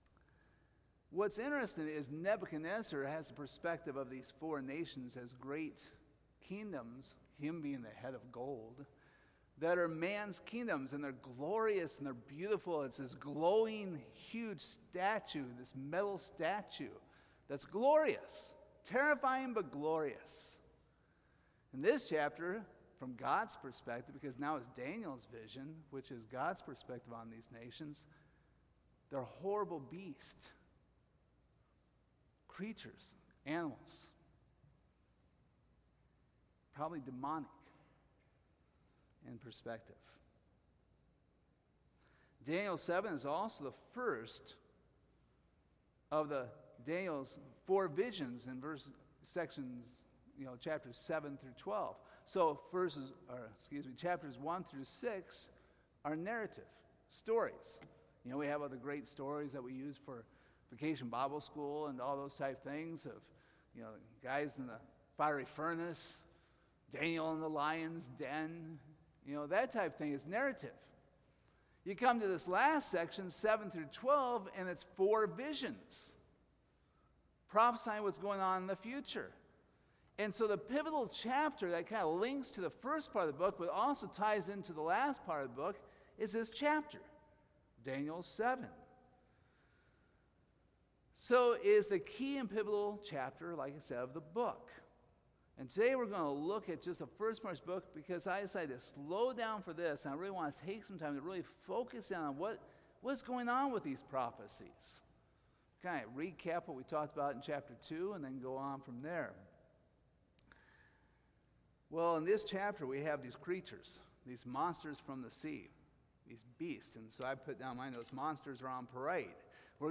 Service Type: Sunday Evening Topics: Beasts , Prophecy